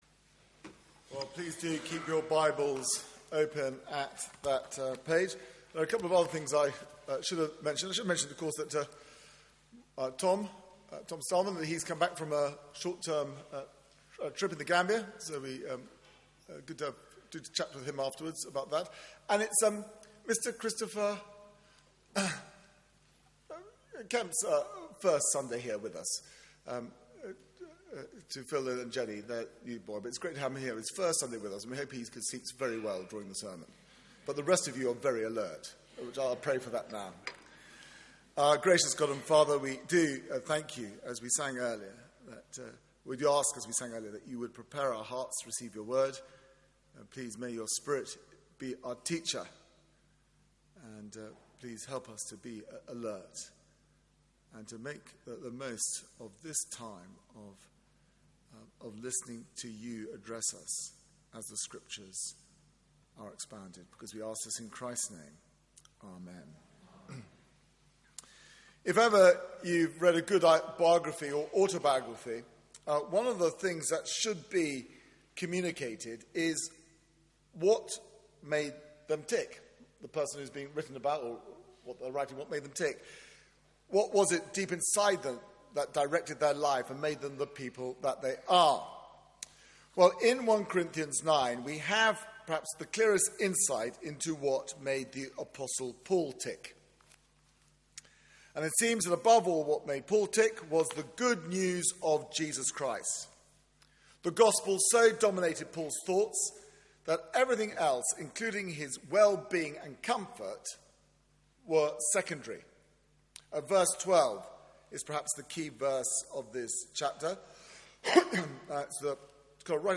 Media for 9:15am Service on Sun 31st May 2015